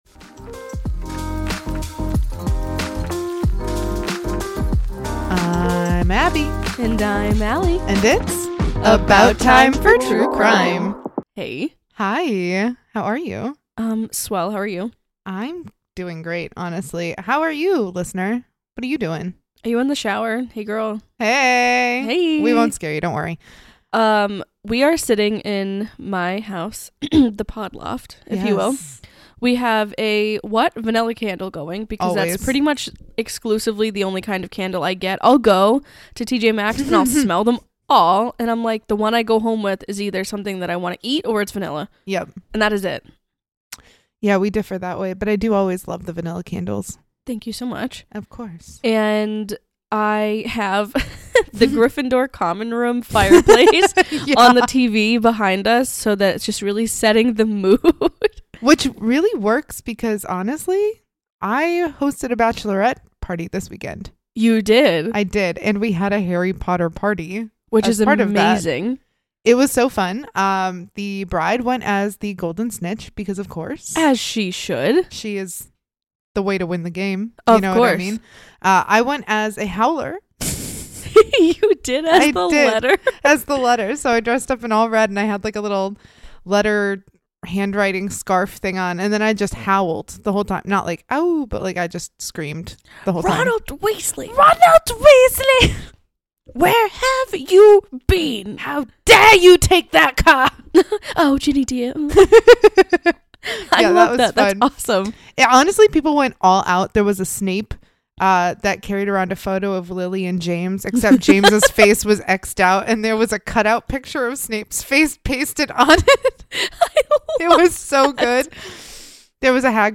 It’s about time you heard a true crime podcast from two educated women in the field.
Do you enjoy deadpan, sarcastic humor while digging into gruesome topics?